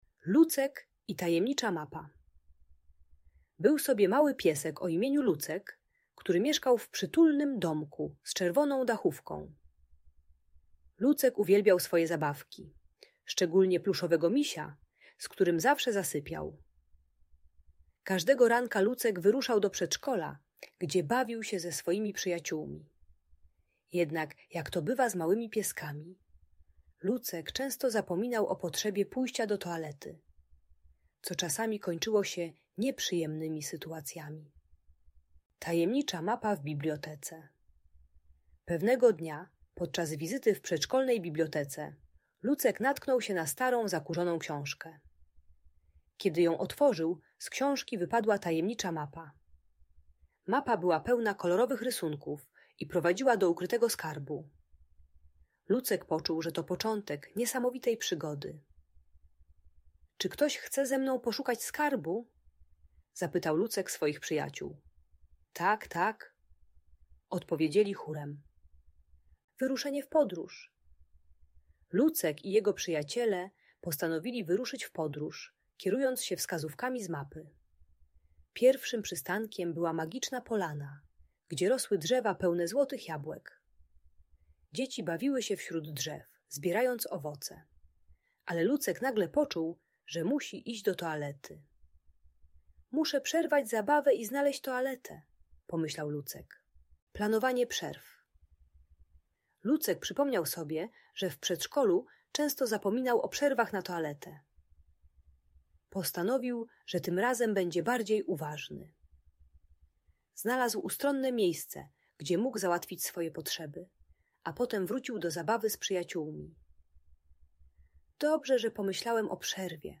Przygody Lucka i Tajemnicza Mapa - Przedszkole | Audiobajka